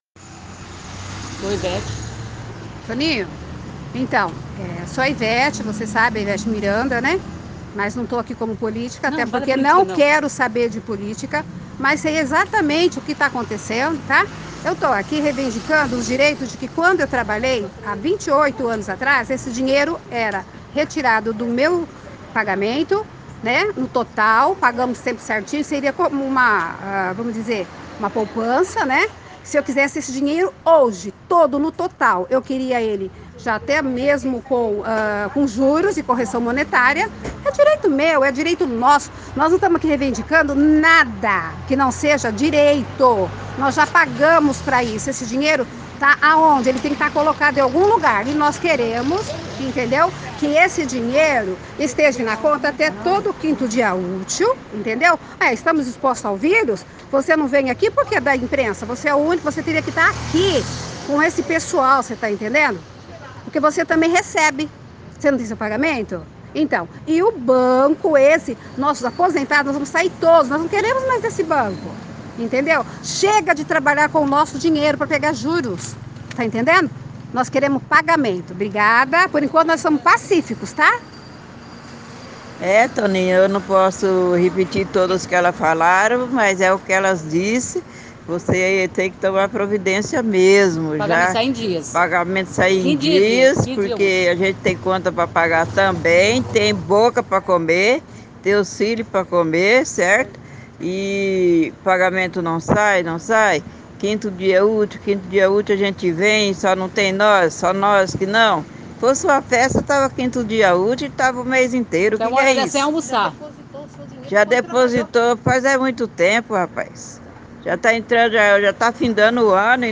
Ouçam a reclamação de pessoas do Ipreven à frente do Bradesco. (O áudio apresenta uma falha em uma das falas. Mas ouça até o final)